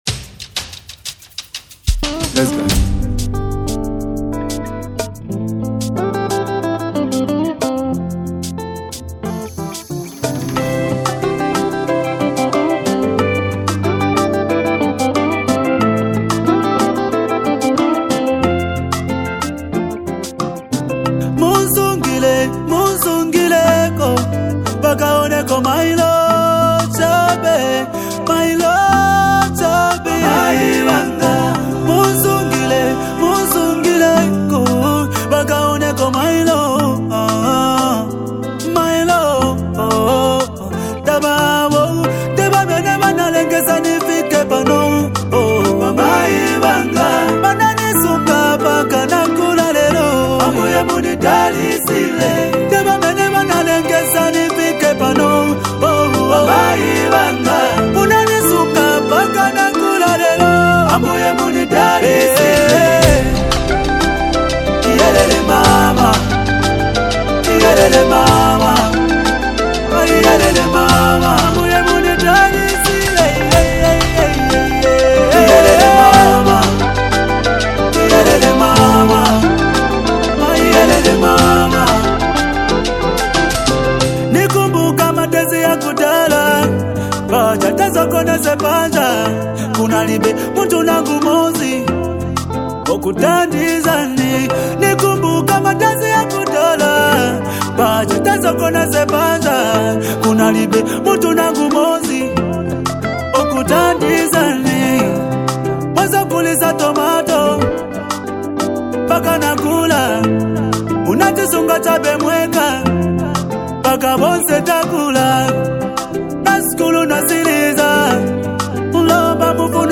particularly in genres like Afrobeat and Zambian pop.
often blending soulful vocals with vibrant rhythms.